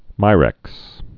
(mīrĕks)